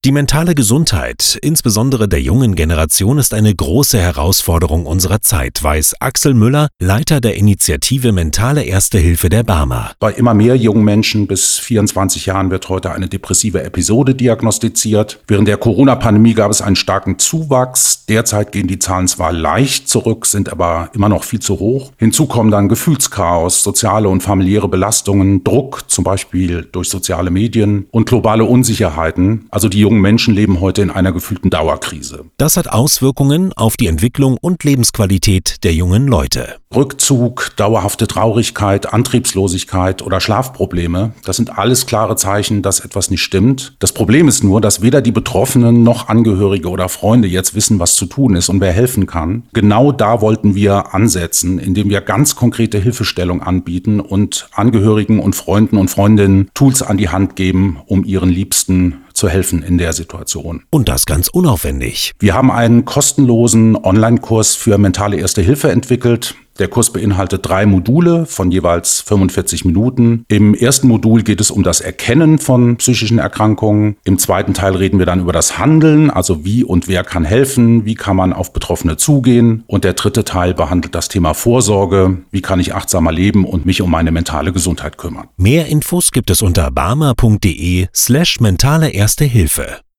Beitrag